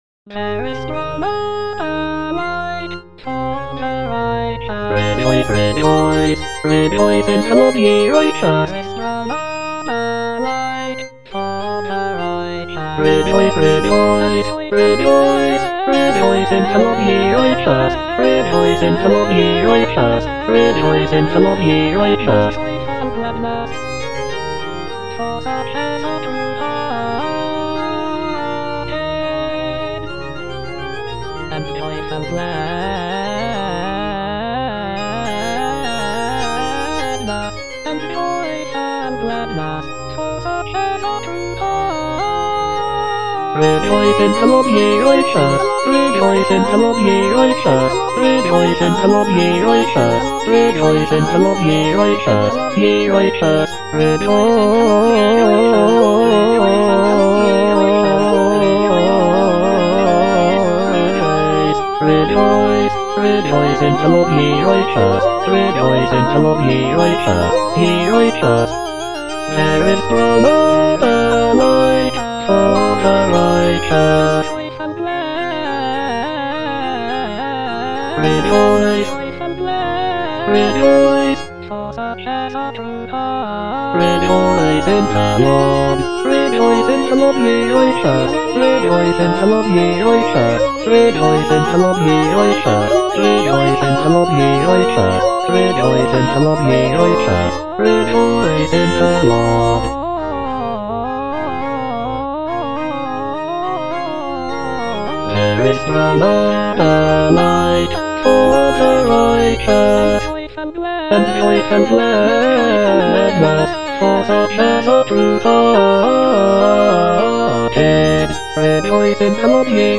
G.F. HÄNDEL - O COME, LET US SING UNTO THE LORD - CHANDOS ANTHEM NO.8 HWV253 (A = 415 Hz) There is sprung up a light - Bass (Emphasised voice and other voices) Ads stop: auto-stop
It is a joyful and celebratory piece, with uplifting melodies and intricate harmonies.
The use of a lower tuning of A=415 Hz gives the music a warmer and more resonant sound compared to the standard tuning of A=440 Hz.